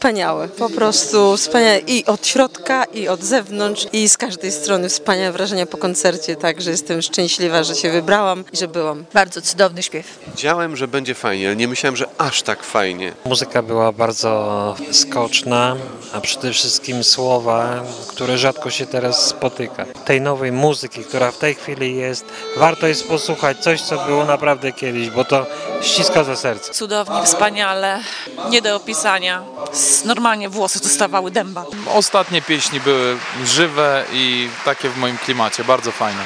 Giżycka publiczność była zachwycona niedzielnym koncertem.